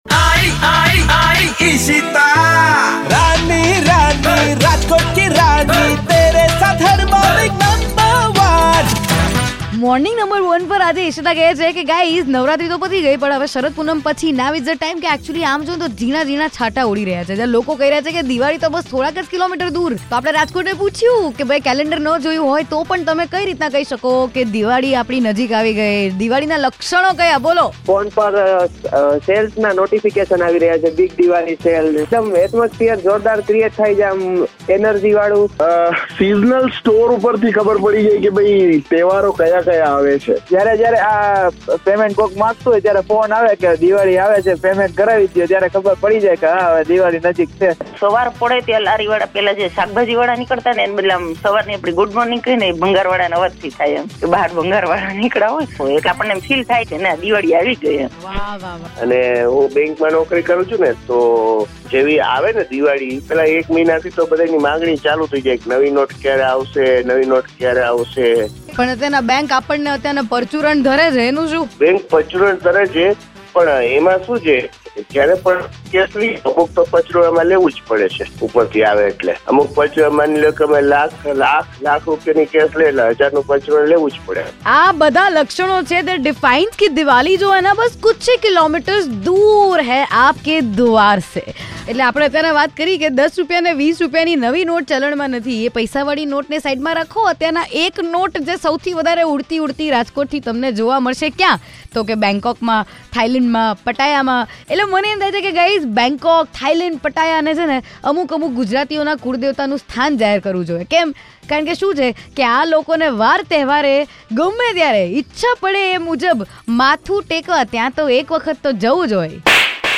After Navratri, Diwali is just few kilometers away ! Listeners sharing their pre diwali observations